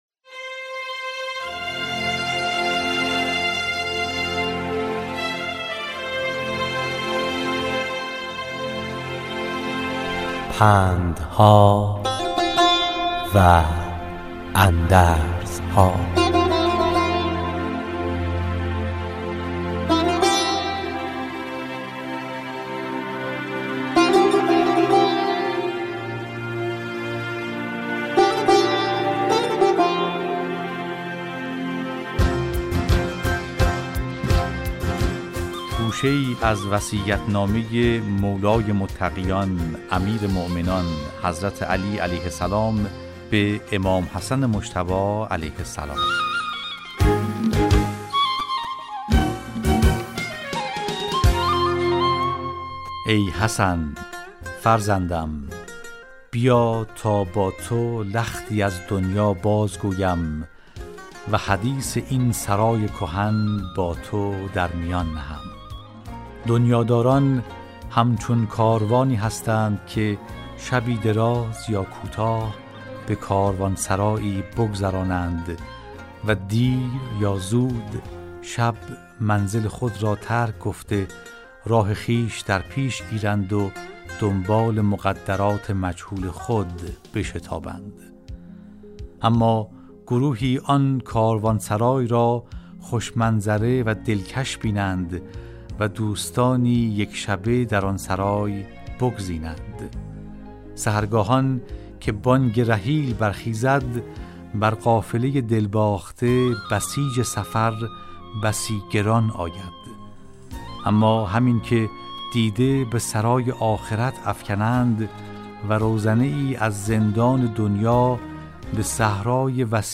Дар барномаи "Пандҳо ва андарзҳо", ровӣ барои шунавандагони азизи Радиои Тоҷикии Садои Хуросон, ҳикоятҳои пандомӯзеро ривоят мекунад.